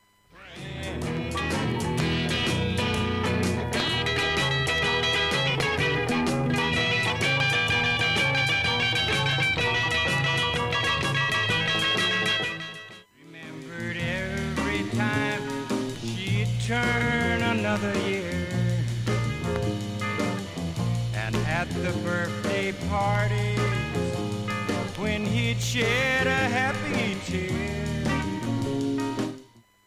音質良好全曲試聴済み。
A-2中盤に4回のわずかなプツが出ます。
A-6序盤にかすかなプツが４回出ます。
◆ＵＳＡ盤 LP, Album, Mono
ブルース・ロック・バンド